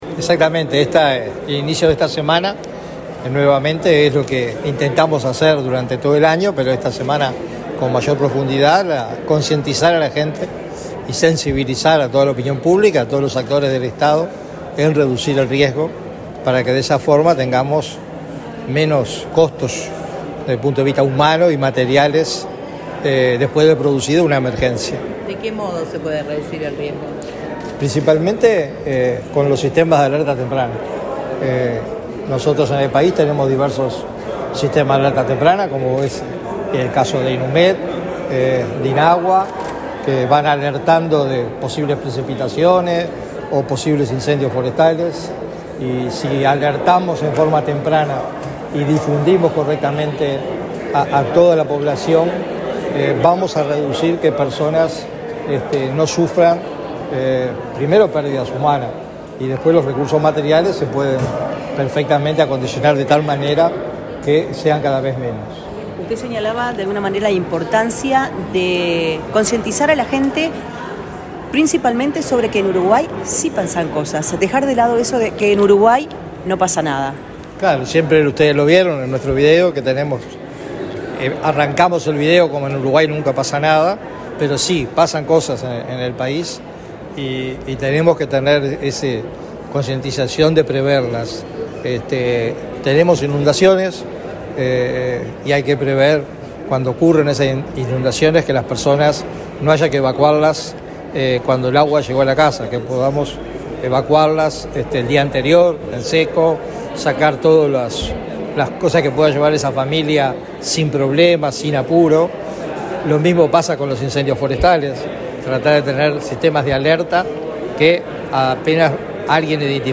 Declaraciones del presidente del Sinae
Luego dialogó con la prensa.